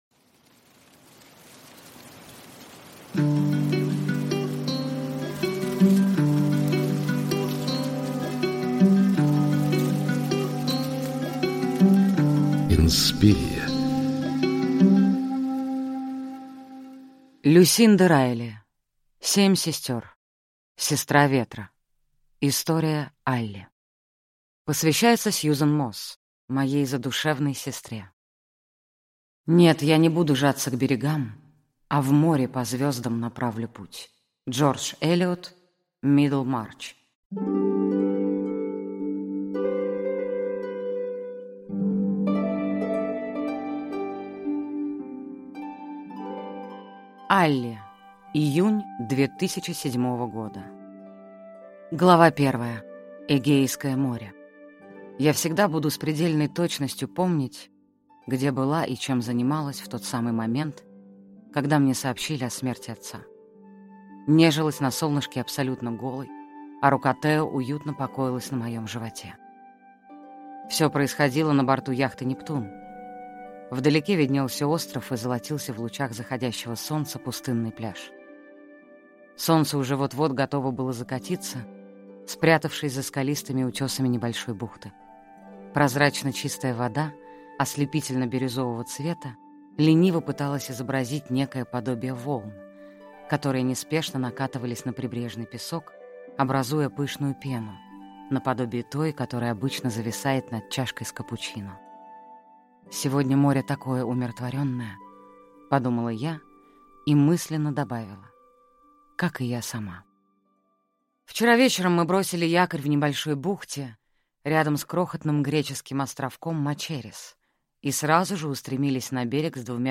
Аудиокнига Семь сестер. Сестра ветра | Библиотека аудиокниг